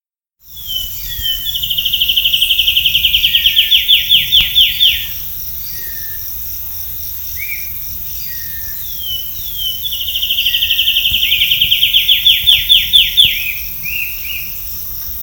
Pula-pula-ribeirinho (Myiothlypis rivularis)
Nome em Inglês: Riverbank Warbler
Província / Departamento: Santa Catarina
Condição: Selvagem
Certeza: Gravado Vocal
Aranero-Ribereno.mp3